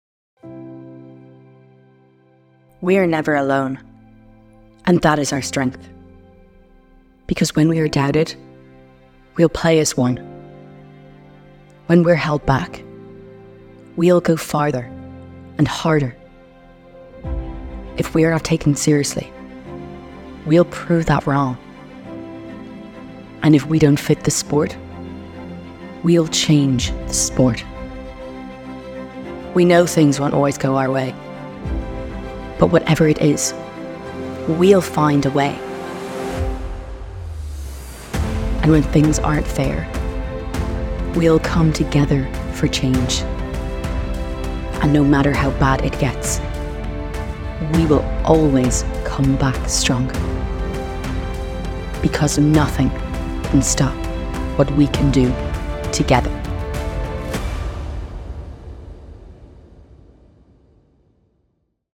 Female
20s/30s, Teens
Irish Dublin Neutral, Irish Neutral